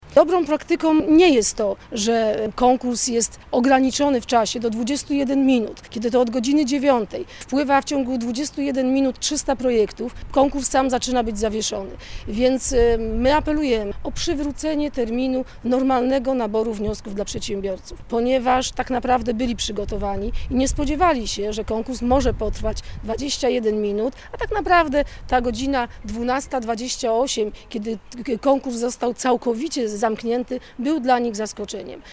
Radna apelowała dziś na konferencji prasowej o wznowienie przyjmowania wniosków: